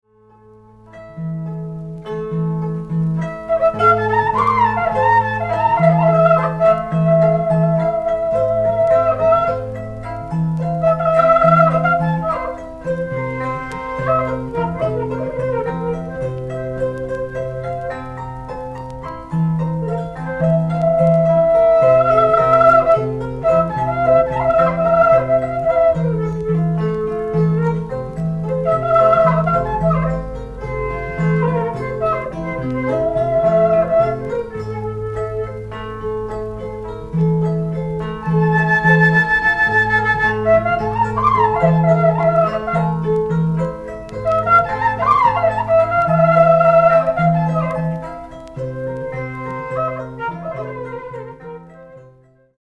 18弦の舟形ツィター『カチャピ』と、循環呼吸によって切れ目なく吹き鳴らされる『スリン』と呼ばれる竹笛による合奏を収録。
民族音楽